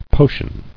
[po·tion]